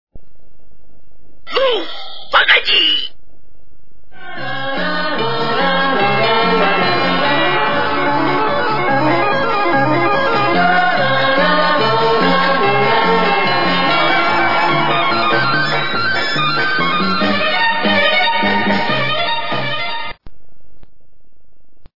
Nhạc Chuông Chế Hài Hước